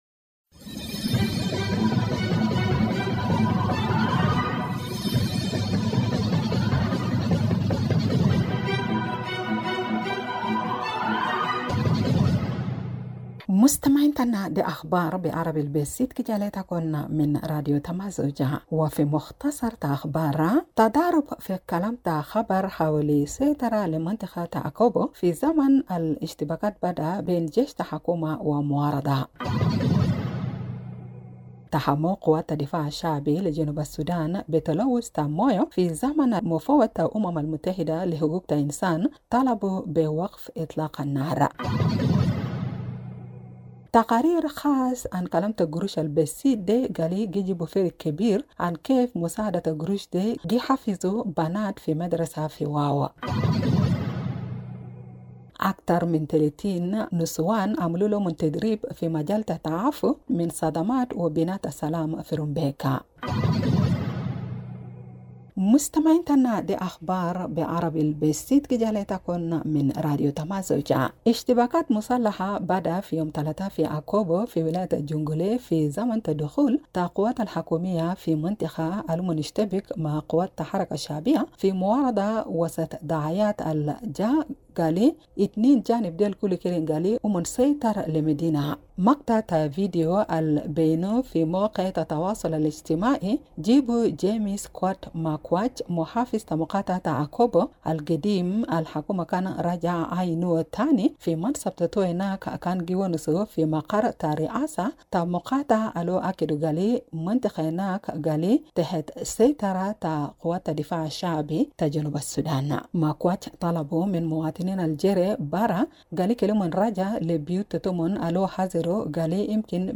Evening Broadcast 11 March Juba Arabic News